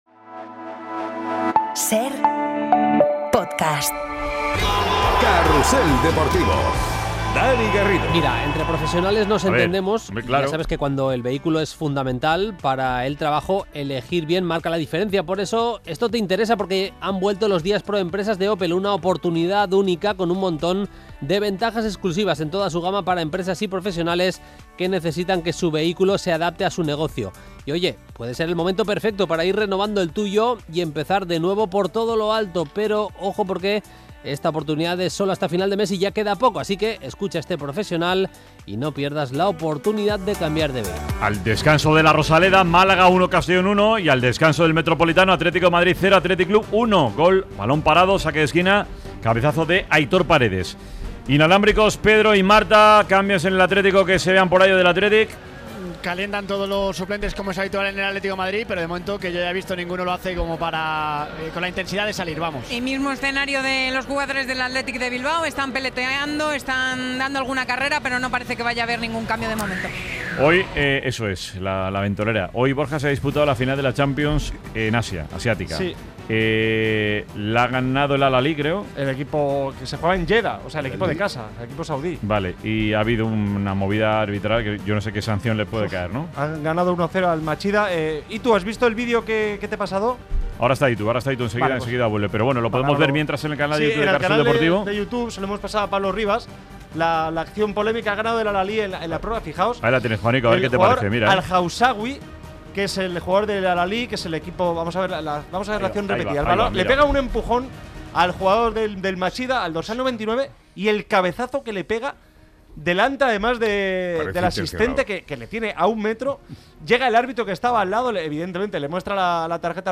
Protagonistas, análisis y reacciones del triunfo del Atlético de Madrid ante el Athletic Club en el Metropolitano por 3-2. Además, charlamos con dos protagonistas del día: Cata Coll y Dani Mérida. Los miembros de 'El Sanedrín' analizan la victoria del Barça en Getafe por 0-2 y las consecuencias de un nuevo 'pinchazo' del Real Madrid.